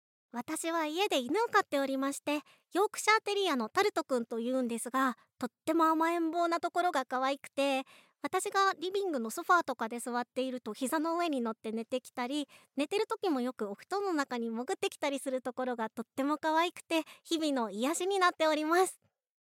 ボイスサンプル
フリートーク